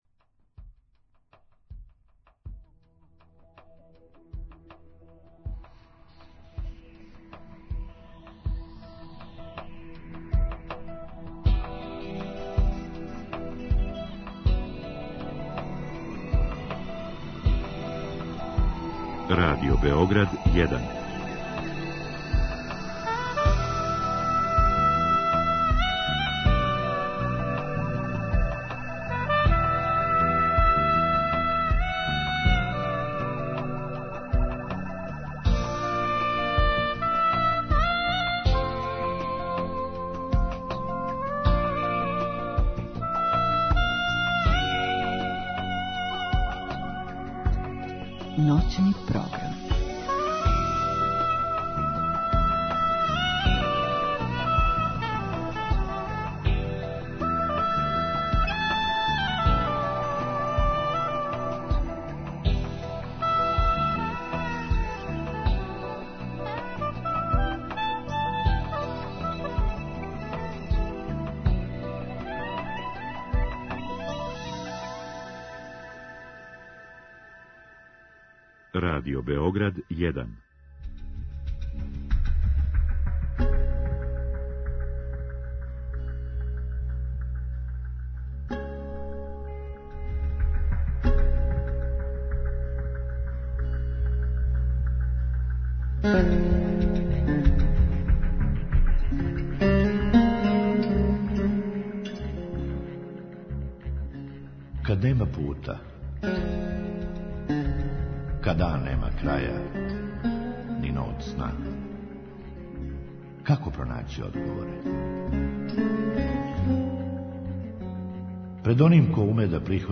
У другом сату ће слушаоци имати прилику да у директном програму гошћи поставе питање у вези са темом.